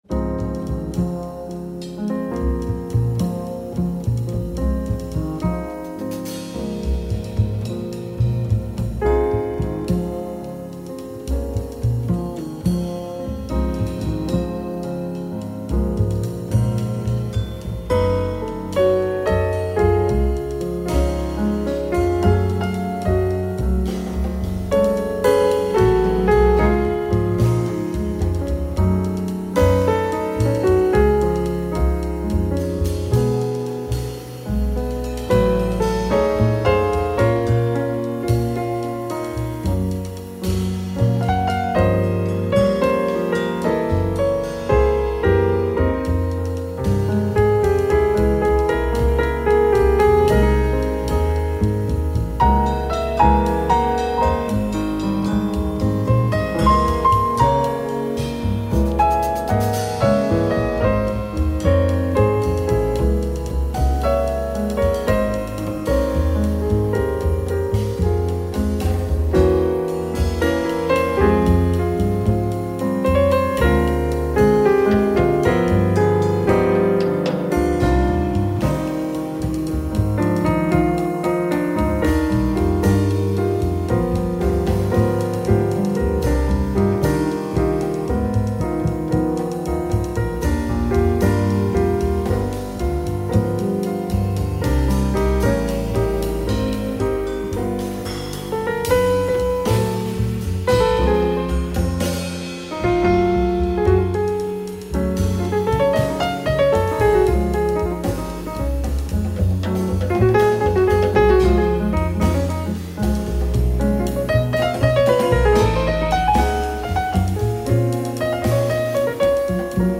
Contemporary piano trio.
bass
drums